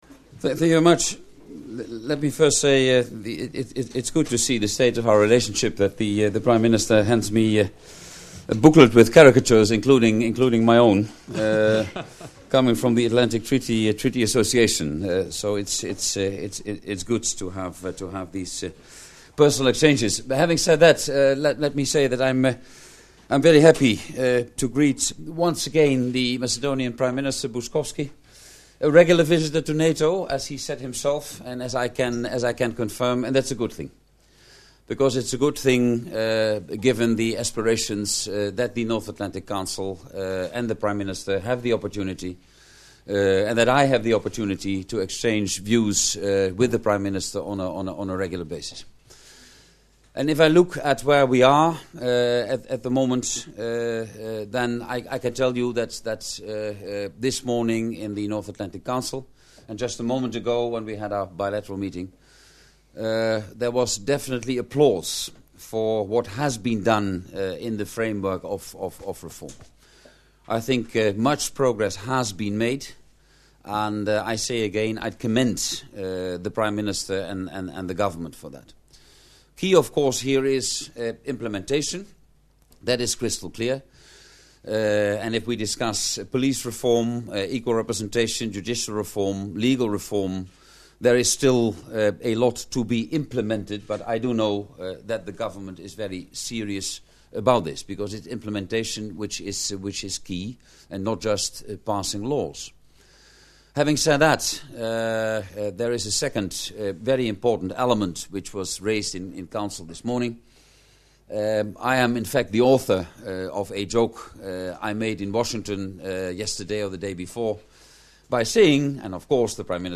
Press point by NATO Secretary General, Jaap de Hoop Scheffer and Vlado Buckovski, Prime Minister of the former Yugoslav Republic of Macedonia¹
From the event Visit by Prime Minister Buckovski